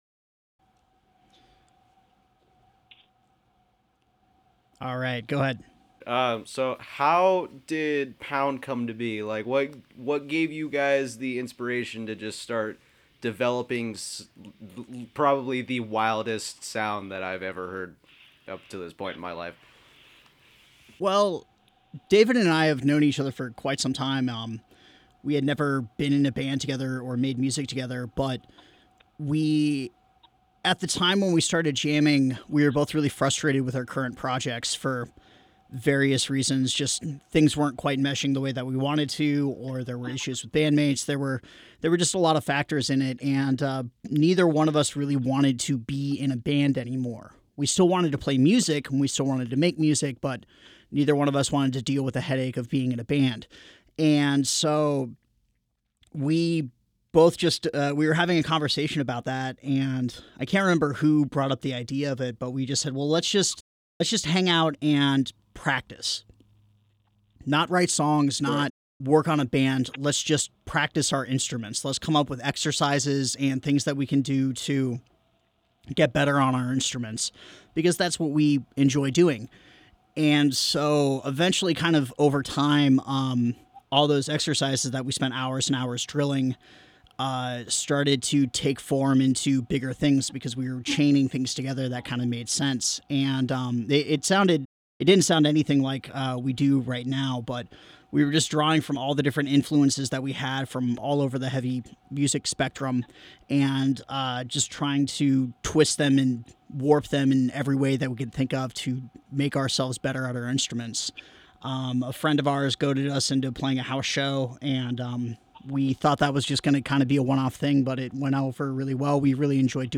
Now normally these interviews are written, however this time around we went a different route and have a fully recorded interview for your listening pleasure.